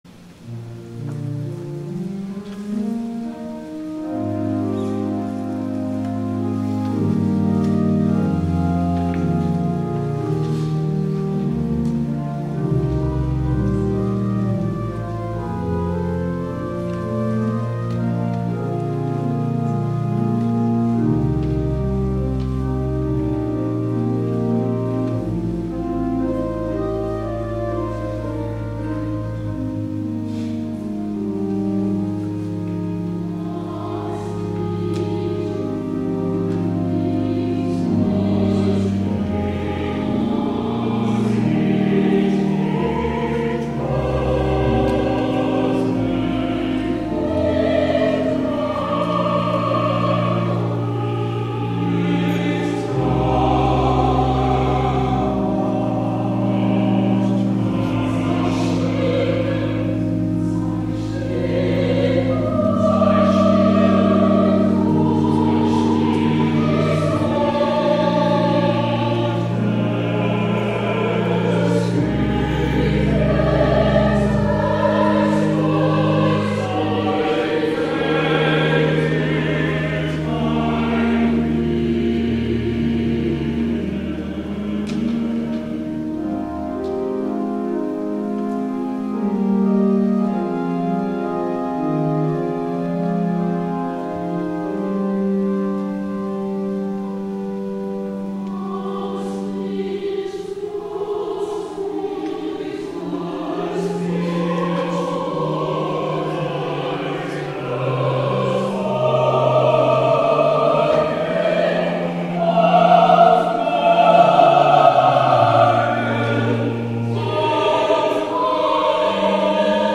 THE ANTHEM
In the Brahms setting the composer uses the interval of a diminished fifth throughout the piece to convey the sadness of the text.